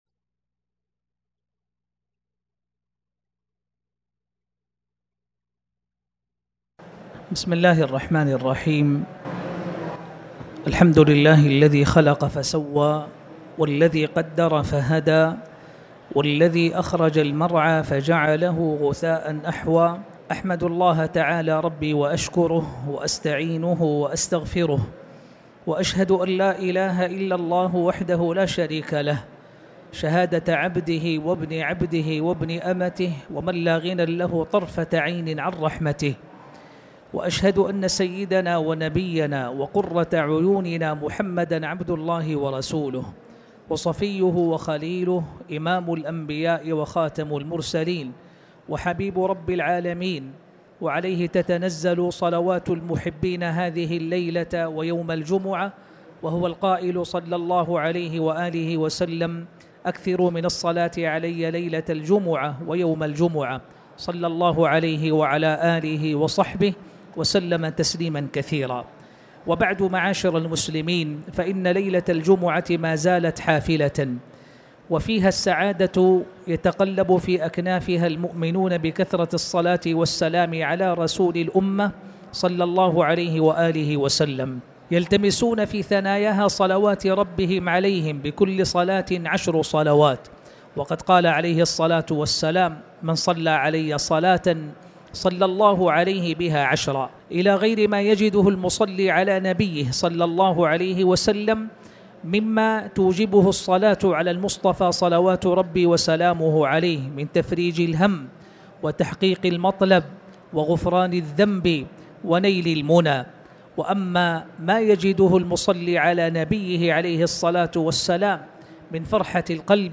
تاريخ النشر ٢٠ صفر ١٤٣٩ هـ المكان: المسجد الحرام الشيخ